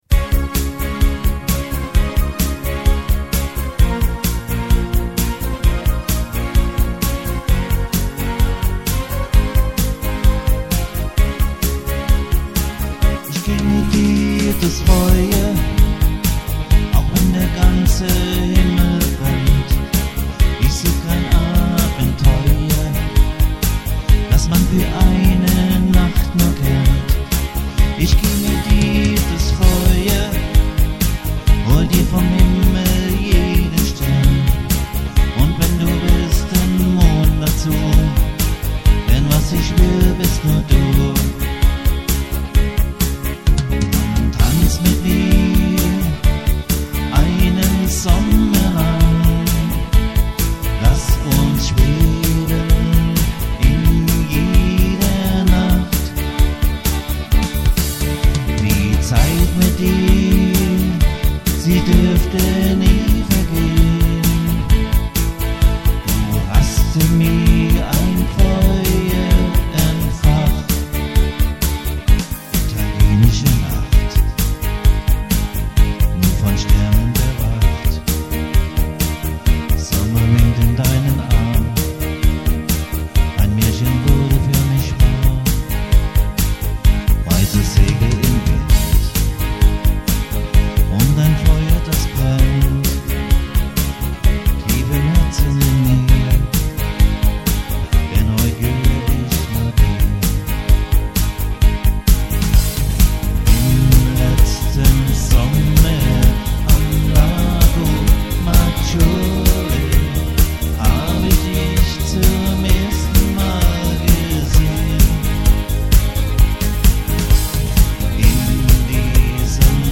- Livemusik mit Gesang
• Alleinunterhalter